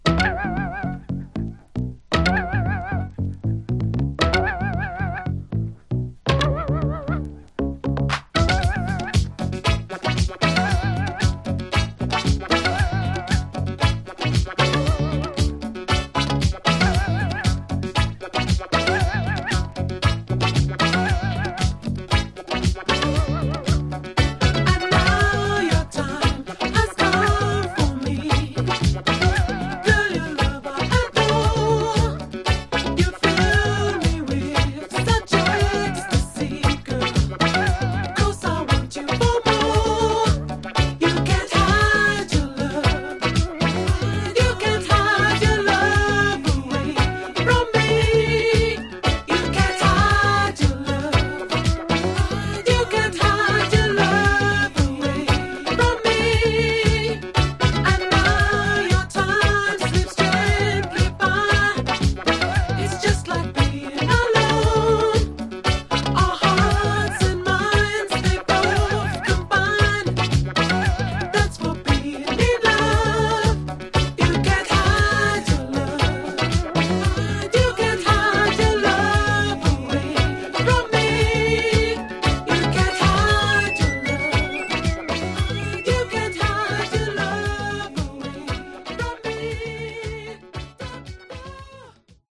Genre: Funk/Hip-Hop/Go-Go